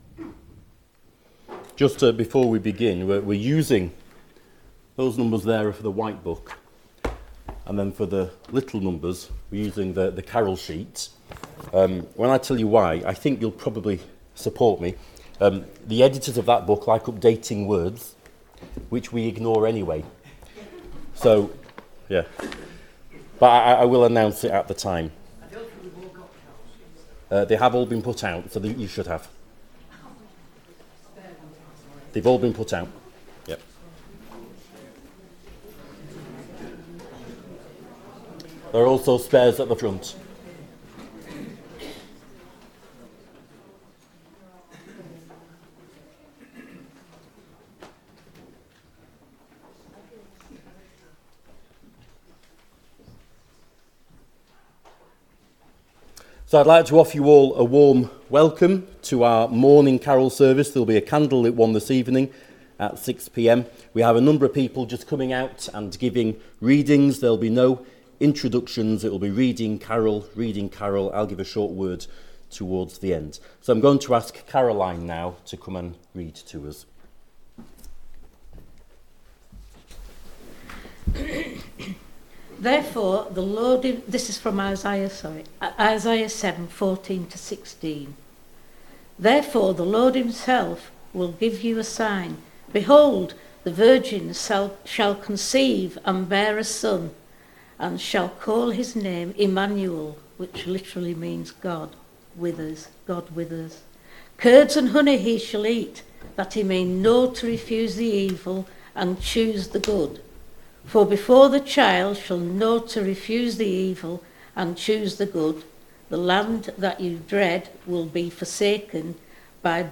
Below is audio of the full service.
2025-12-21 Morning Carol Service If you listen to the whole service on here (as opposed to just the sermon), would you let us know?